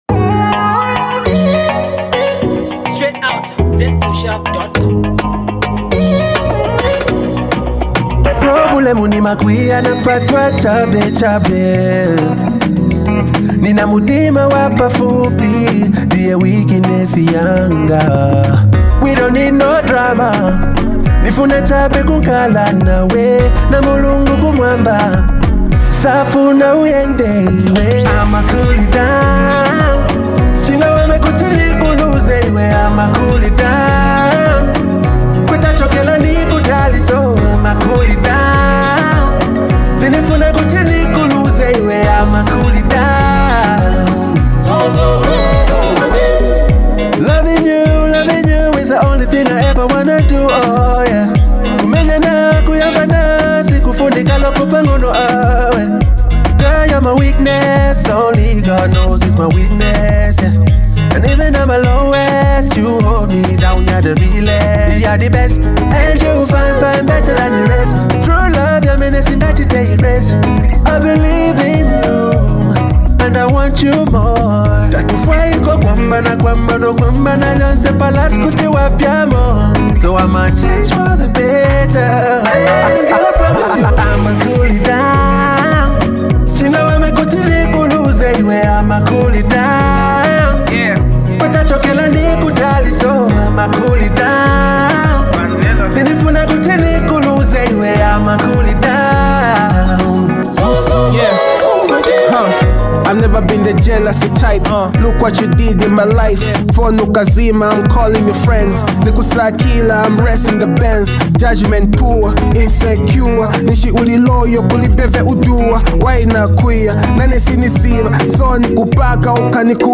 a cool jam